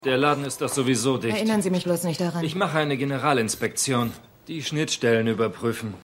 MissionErde_3x03_Techniker.mp3